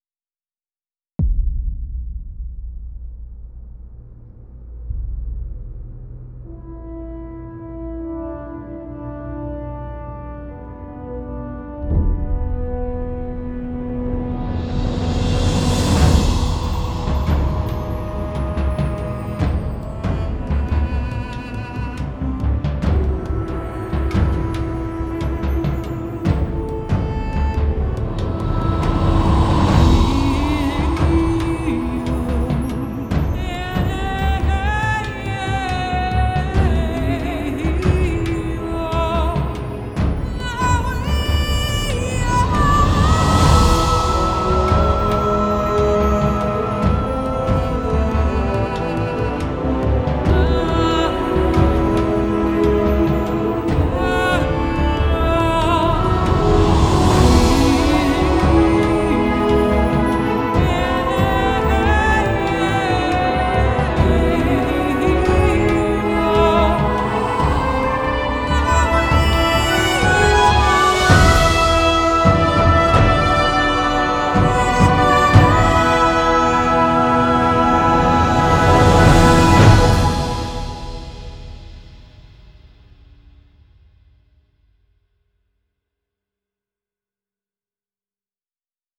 Musique de Film
Musique Électronique, Musique Orchestrale, Science-Fiction.